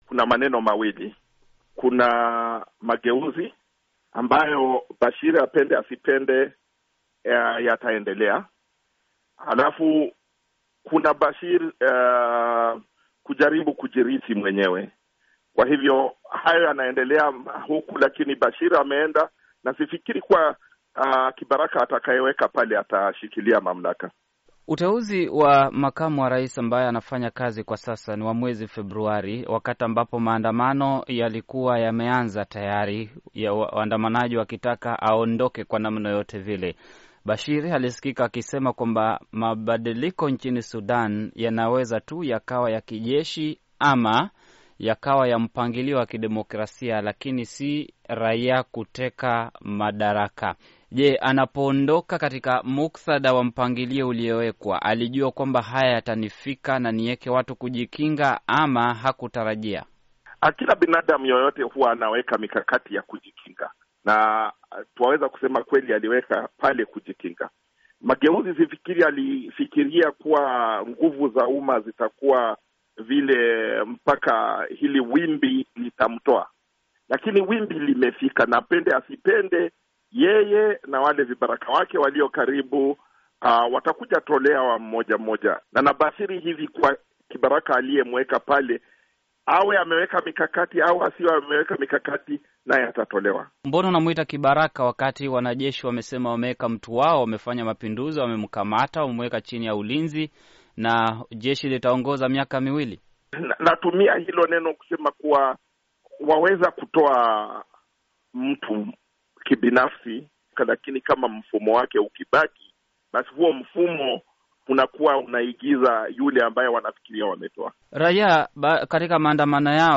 mchambuzi wa siasa